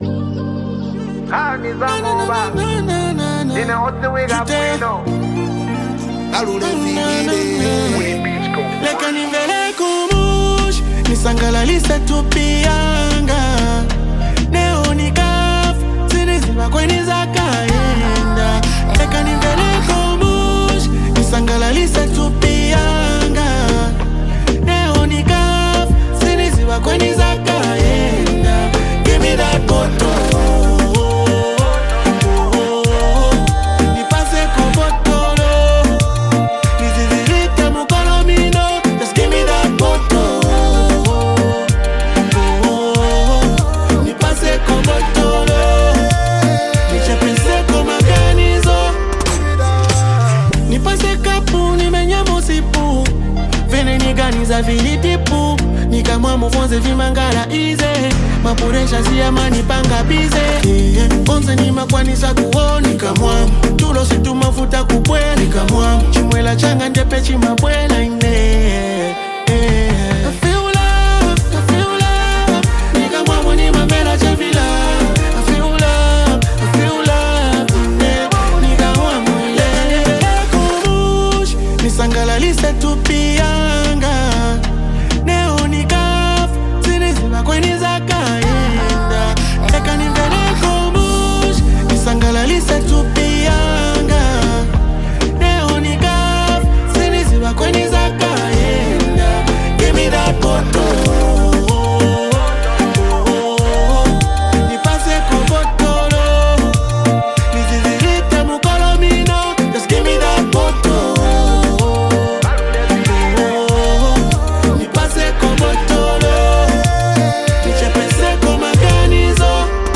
wrapped in catchy melodies and playful lyricism.
soulful touch and expressive delivery